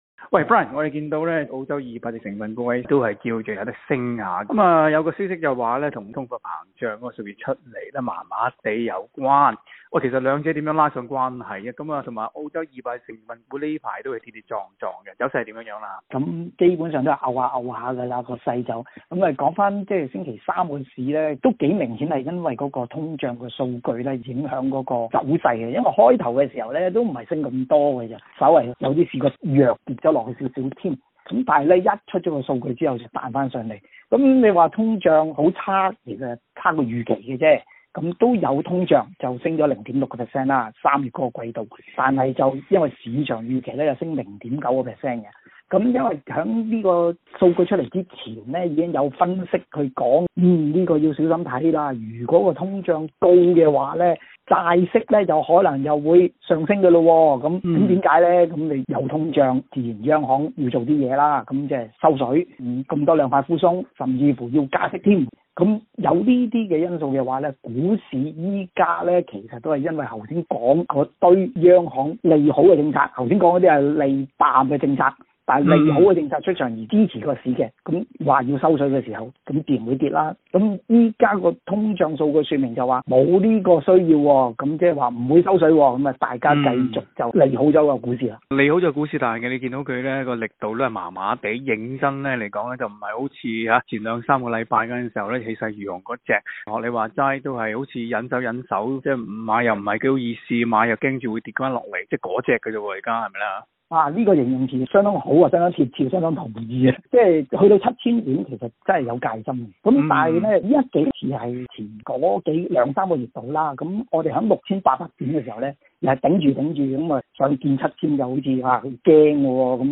詳情請收聽今日的訪問内容